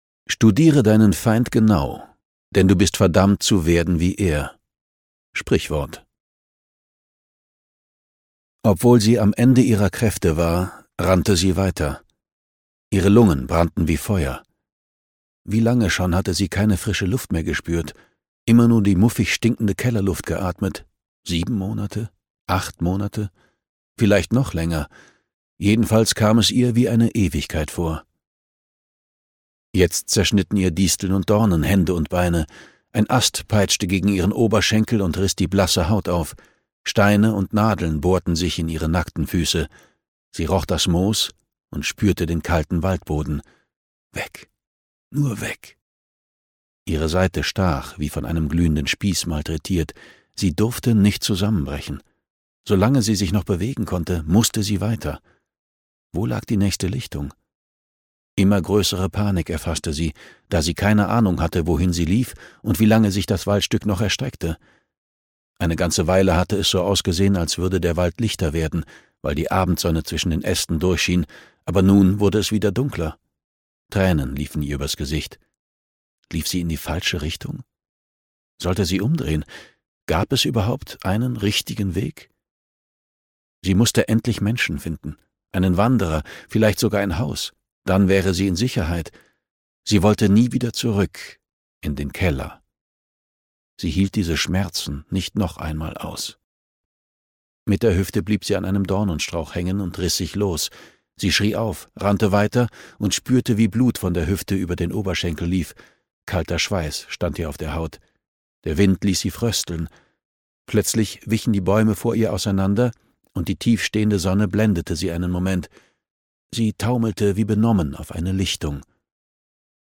Todesurteil (DE) audiokniha
Ukázka z knihy